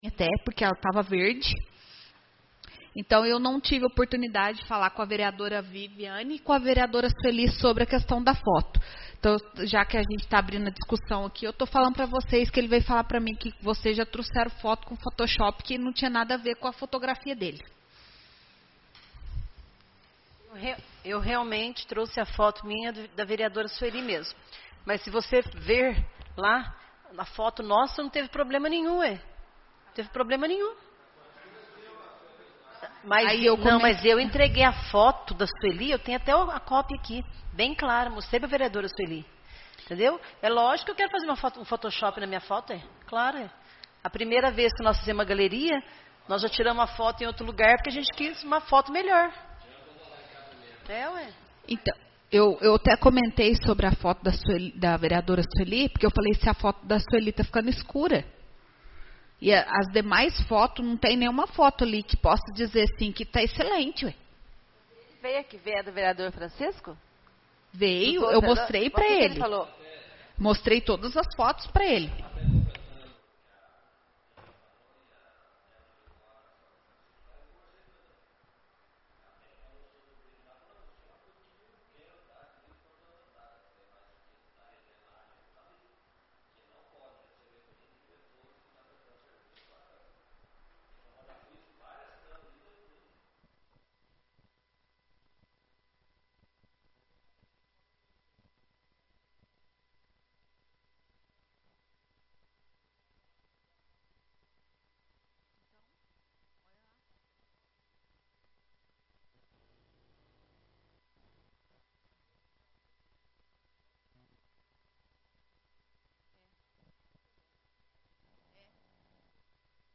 Áudio da 36ª Reunião Ordinária